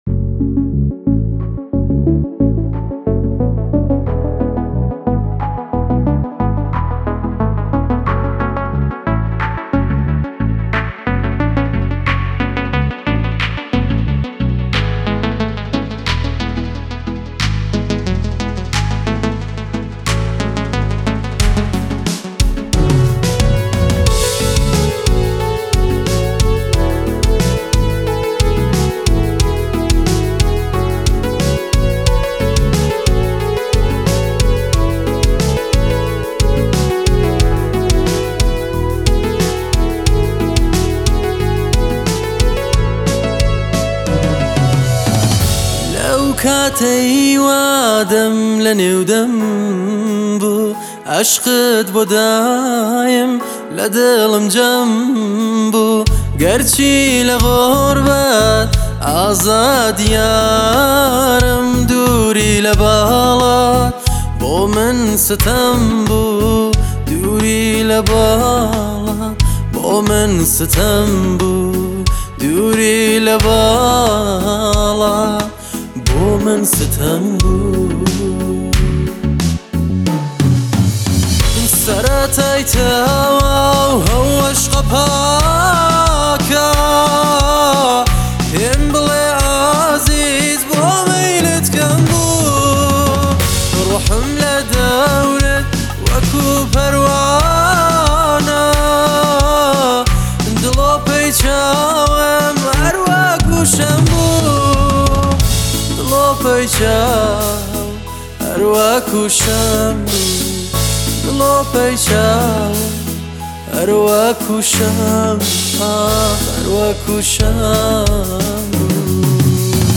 آهنگ کردی جدید